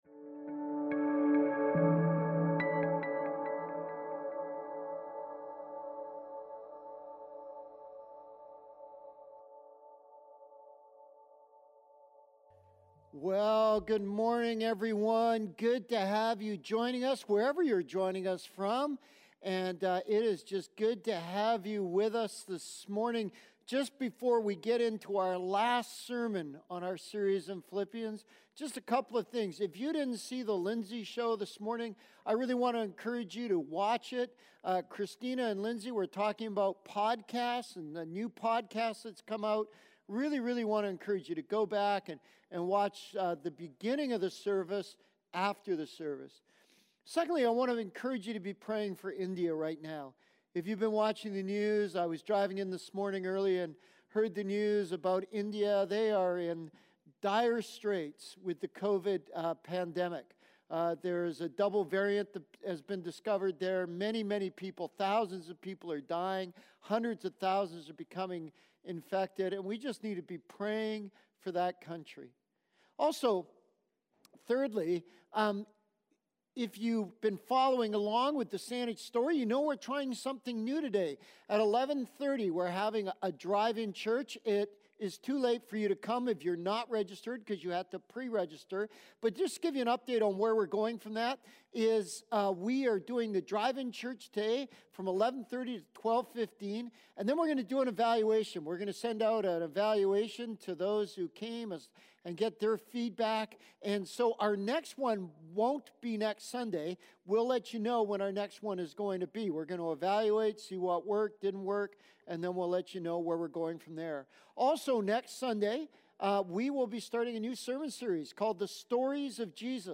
Sermons | Saanich Baptist Church